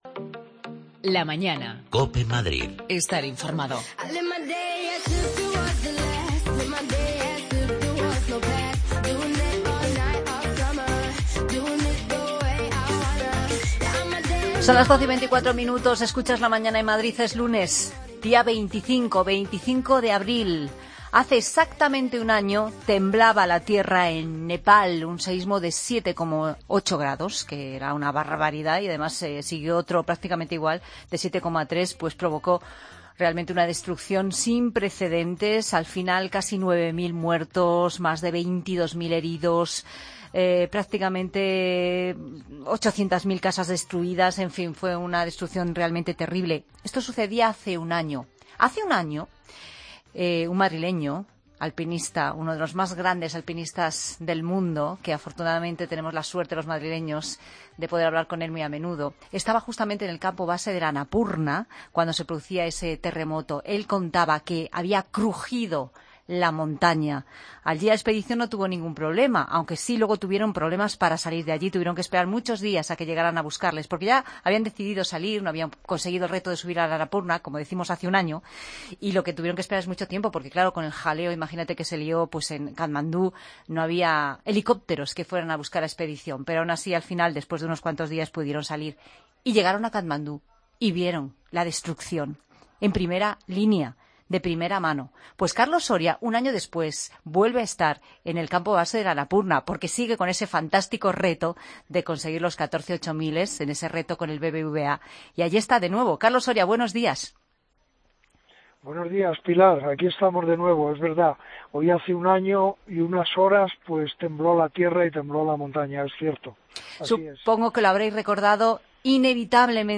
Escucha a Carlos Soria en La Mañana en Madrid desde el Annapurna